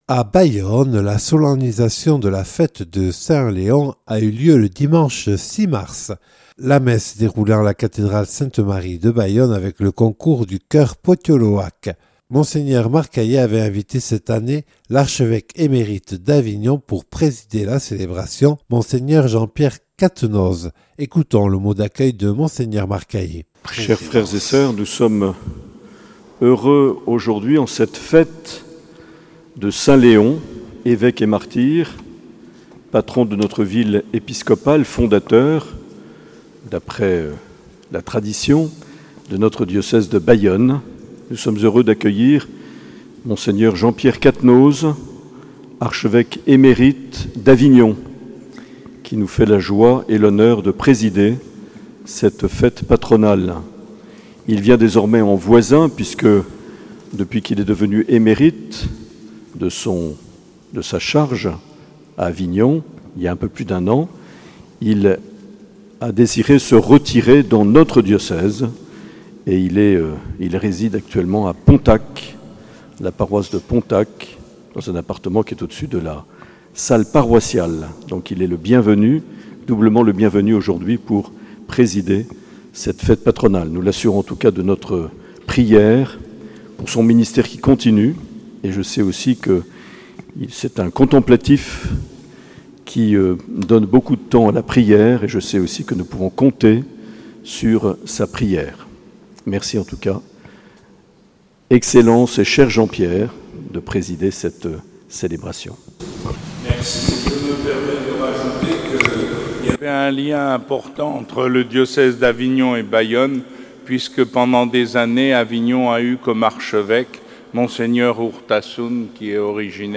Solennité de saint Léon le 6 mars 2022 que présidait Monseigneur Jean-Pierre Cattenoz, archevêque émérite d’Avignon, à la cathédrale de Bayonne
Interviews et reportages